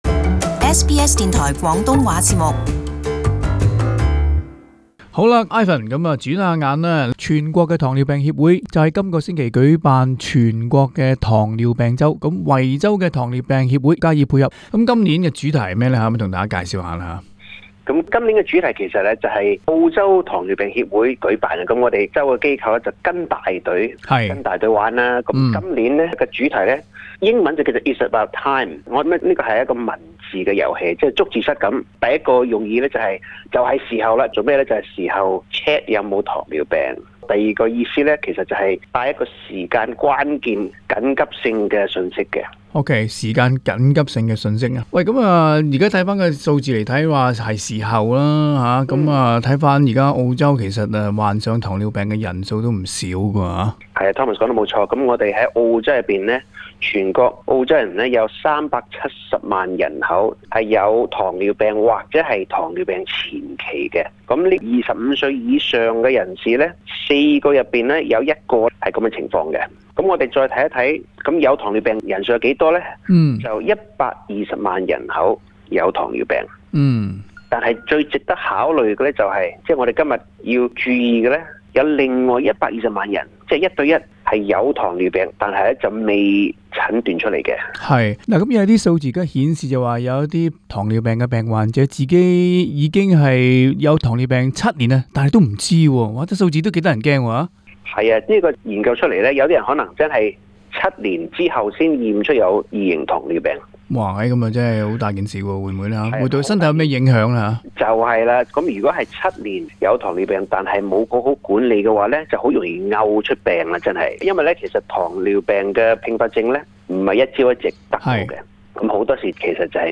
【社區專訪】全國糖尿病周主題：是時候要做體檢了